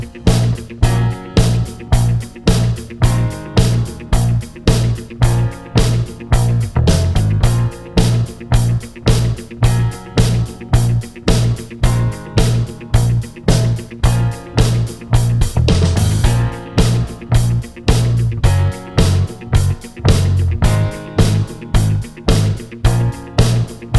Twofers Medley Rock 3:12 Buy £1.50